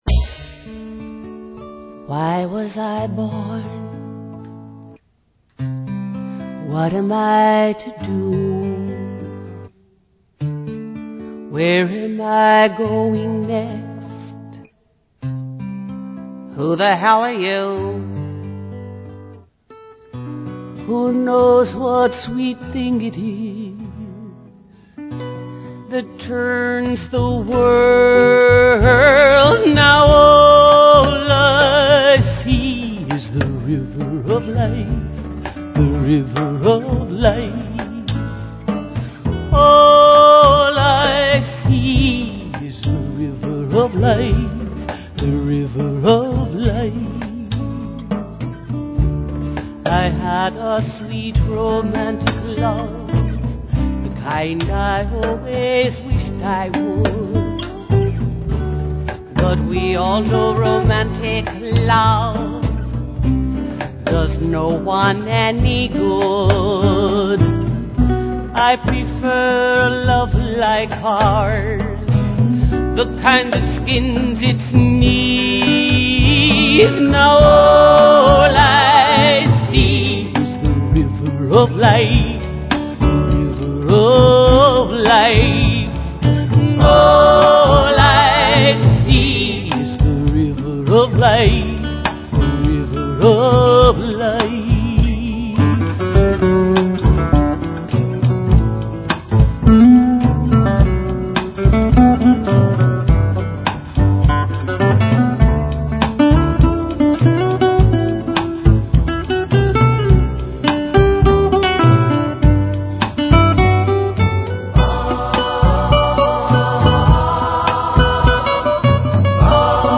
Talk Show Episode, Audio Podcast, Live_from_Ordinary_Miracles and Courtesy of BBS Radio on , show guests , about , categorized as
Join us and our guests, leaders in alternative Health Modalities and Sound Healing, every Wednesday evening at the Ordinary Miracles Store in Cotati,California. We love call in questions!!!